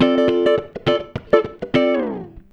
104FUNKY 09.wav